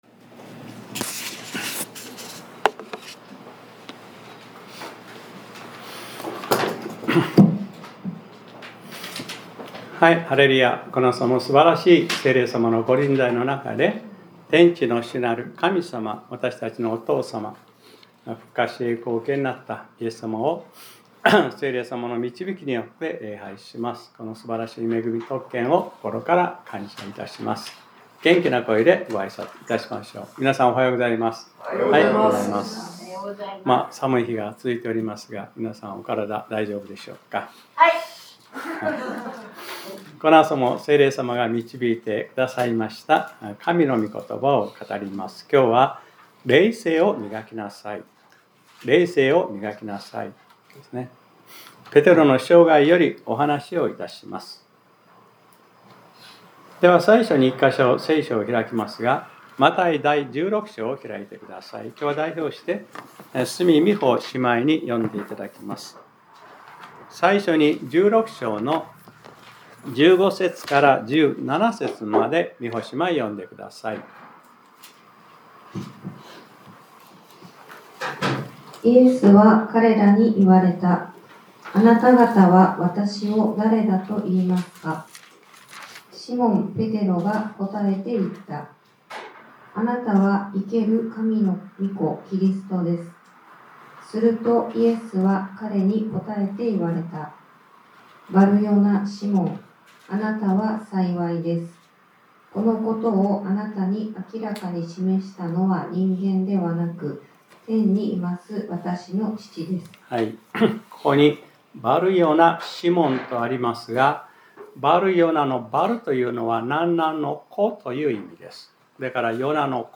2026年01月25日（日）礼拝説教『 霊性を磨きなさい 』